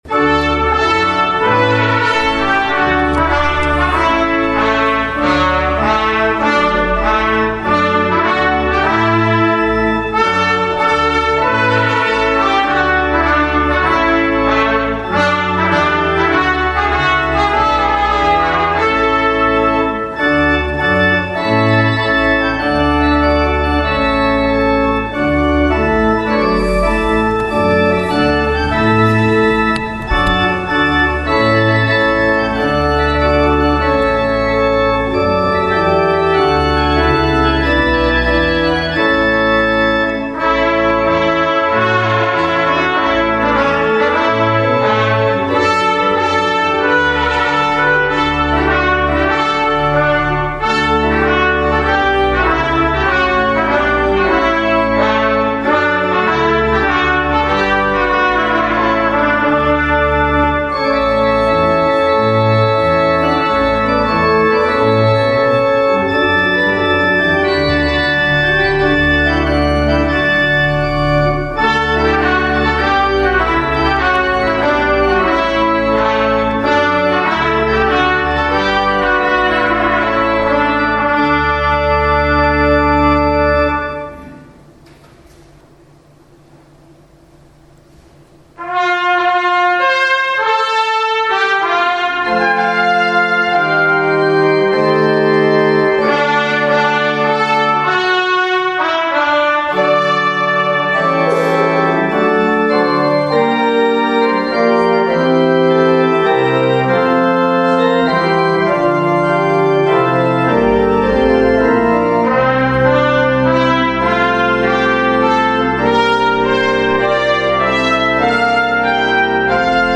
A mai vasárnap CANTATE vasárnapja!
orgona
CANTATE VASÁRNAPJA - Kórusunk szereplése orgonával, trombitaszóval 2024. április 28.